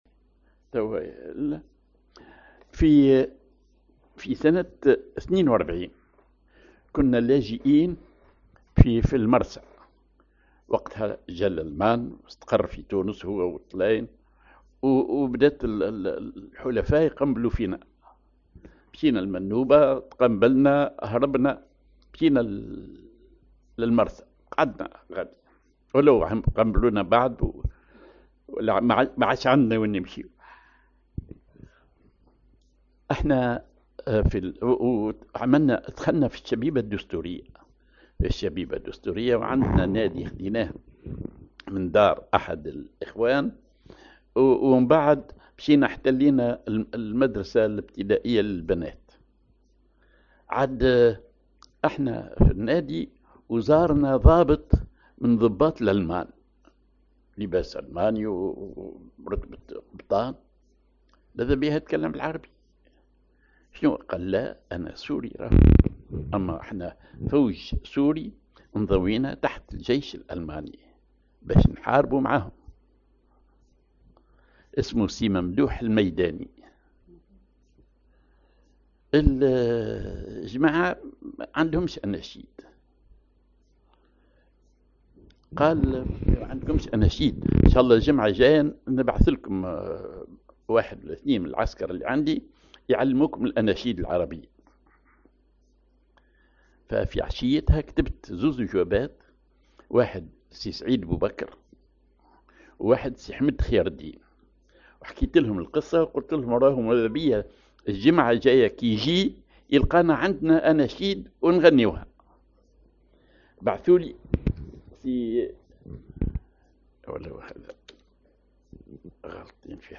Maqam ar ماجور على الجهاركاه
Rhythm ar الخطوة
genre نشيد